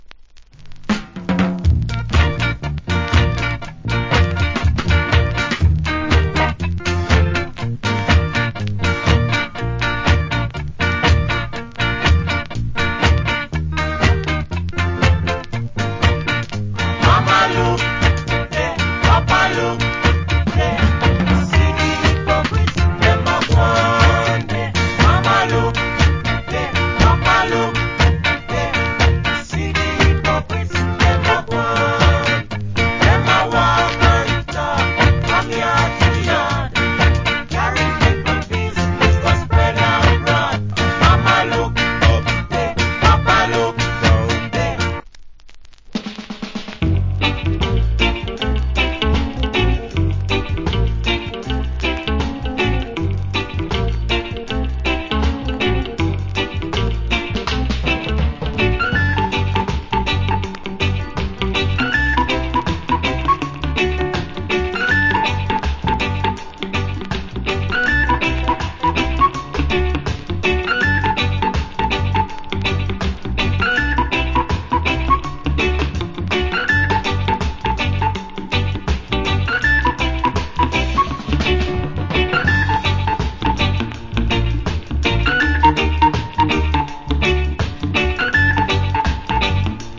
Early Reggae Vocal.